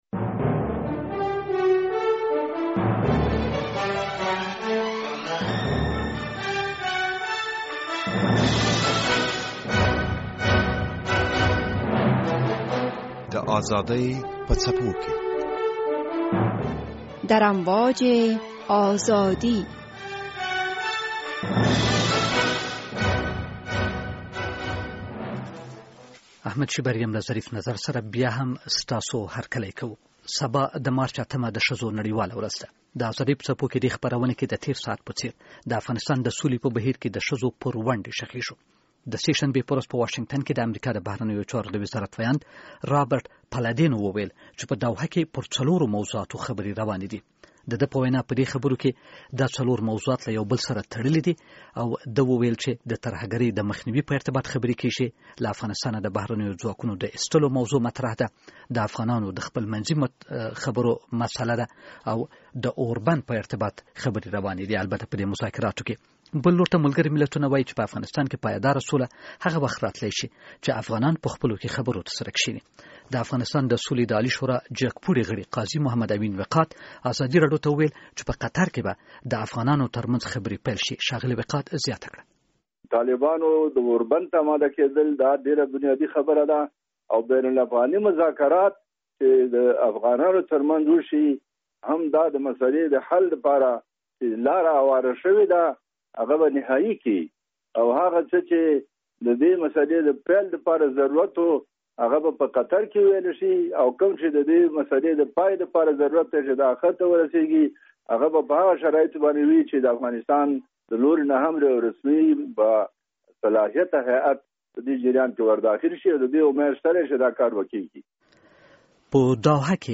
د ازادي په څپو کې د بحث لومړی برخه